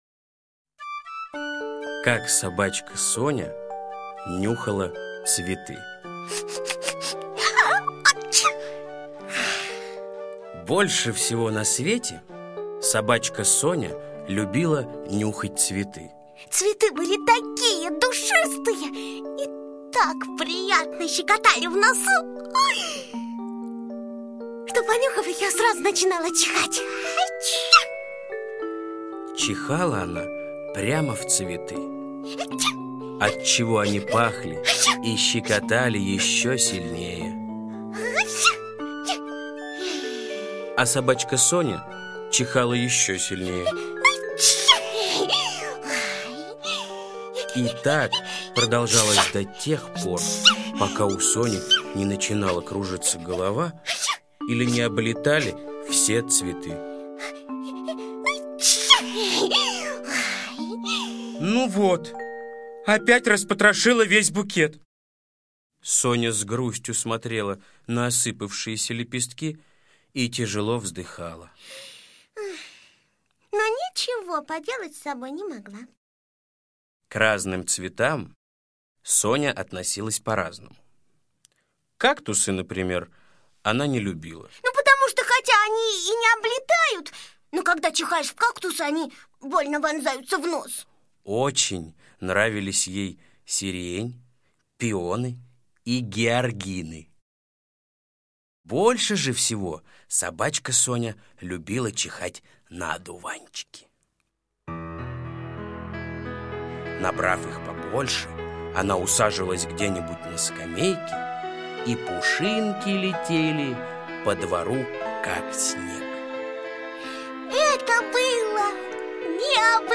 Слушайте Как собачка Соня нюхала цветы - аудиосказка Усачева А.А. Сказка про собачку Соню, которая любила нюхать цветы, а потом в них чихать.